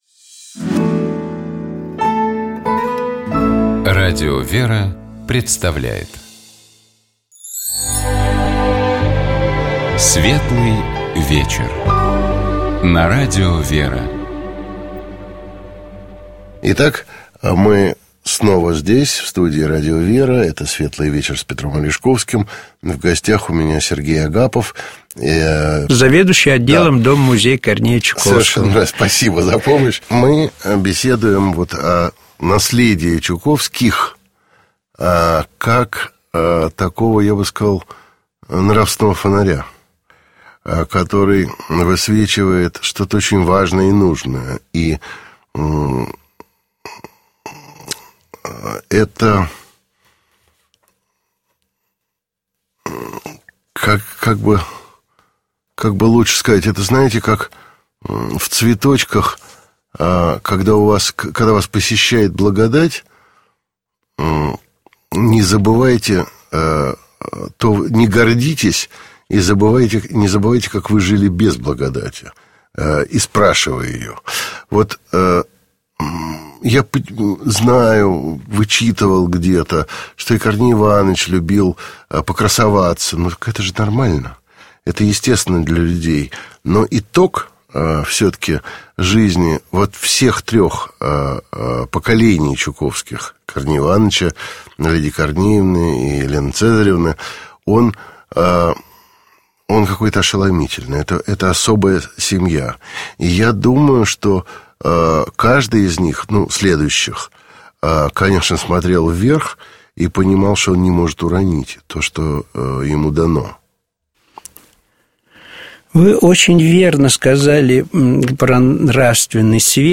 Радио ВЕРА